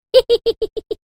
highGiggle.mp3